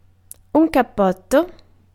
Ääntäminen
IPA : [kəʊt]